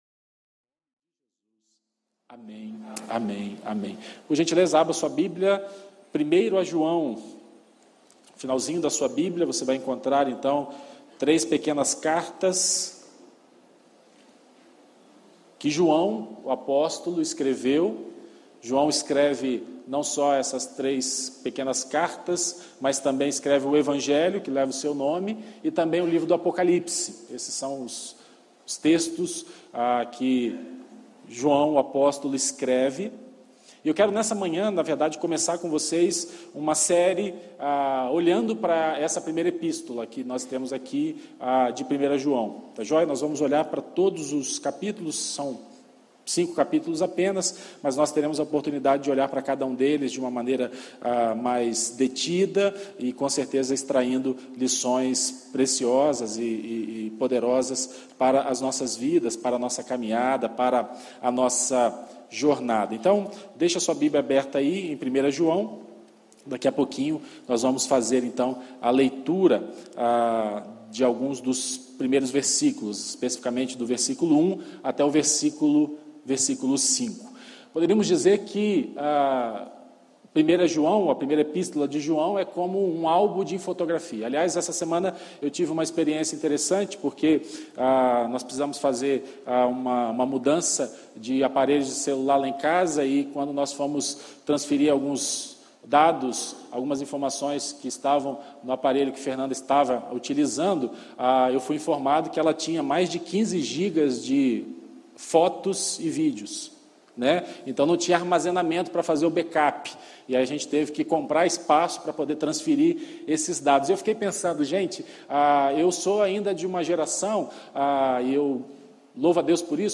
Exposições em 1º João